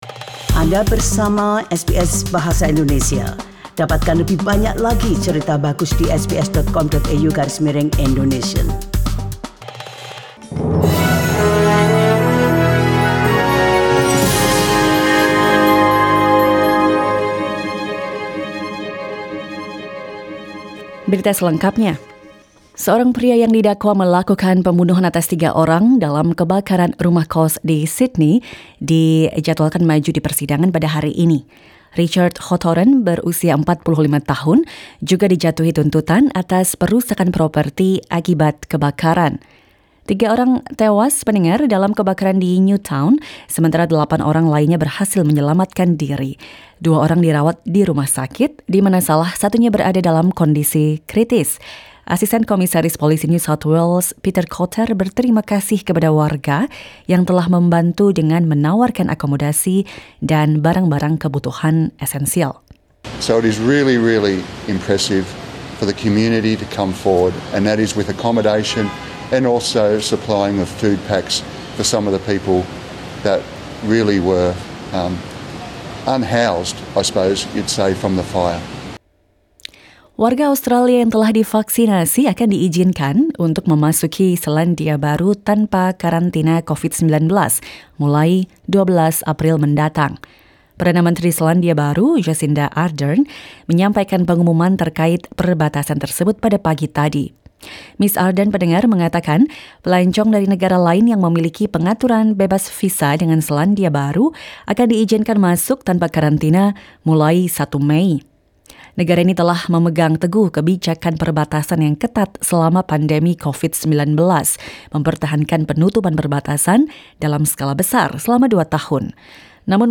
SBS Radio news in Bahasa Indonesia, 16 March 2022.